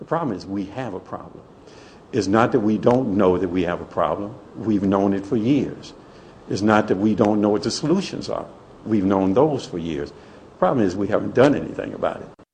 Category: Radio   Right: Both Personal and Commercial